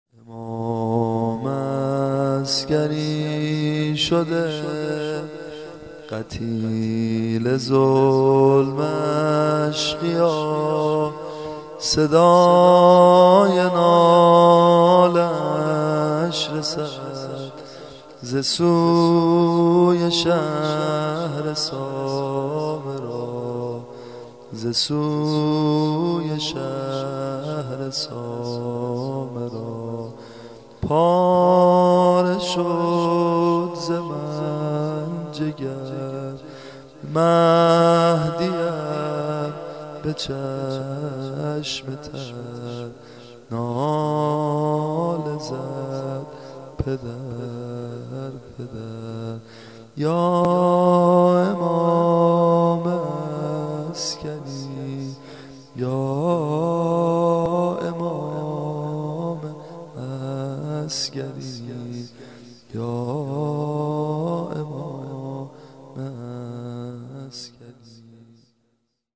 زمزمه شهادت امام عسگری + دانلود سبک